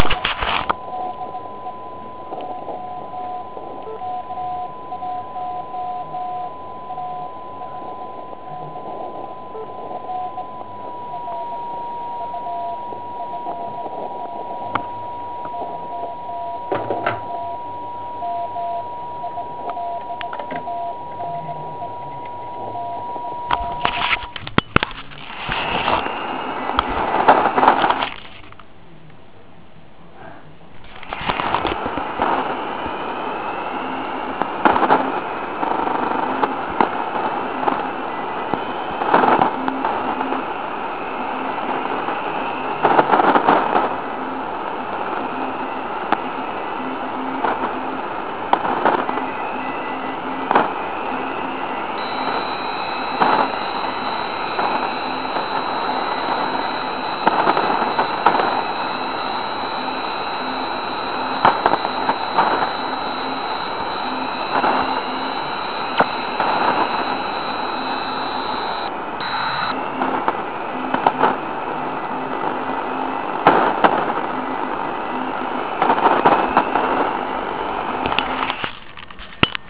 V následující nahrávce máte nejprve poslech na FT817 a v druhé půli poslech na upraveného Rozkmita. Nenechte se však mýlit nízkou výškou zázněje.